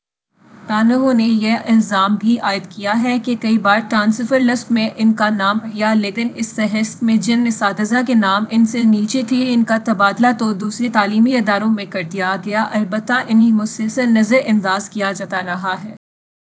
deepfake_detection_dataset_urdu / Spoofed_TTS /Speaker_04 /105.wav